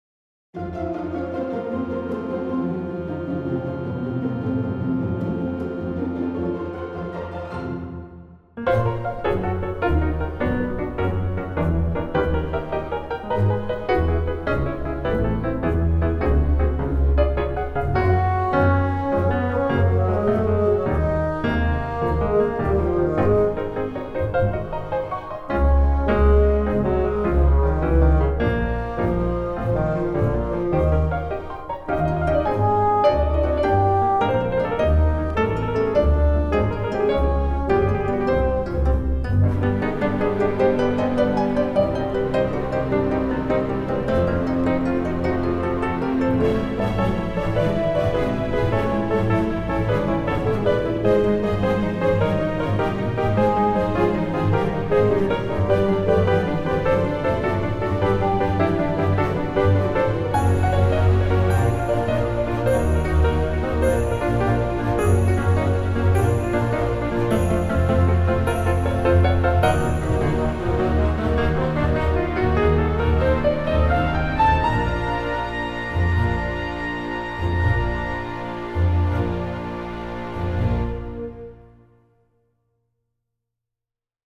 Musician (piano/synths/organ)